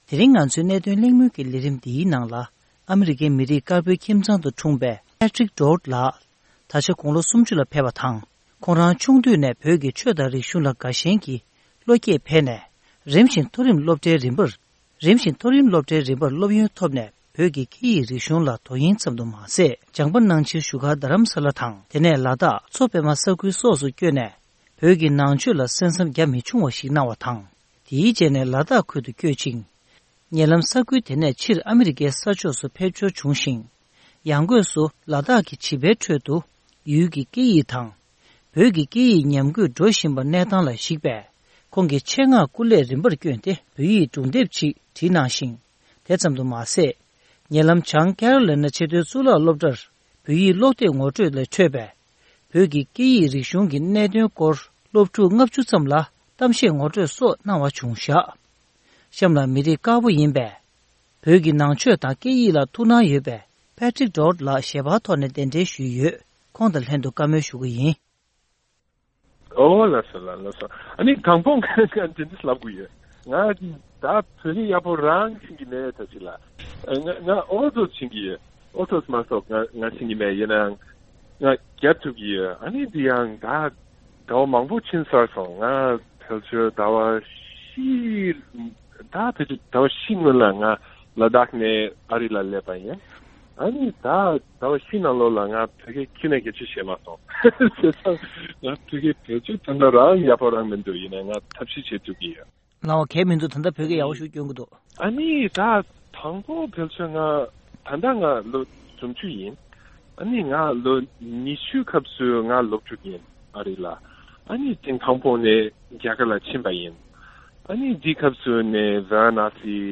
གླེང་མོལ།